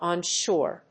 アクセント・音節ón・shóre
オンショア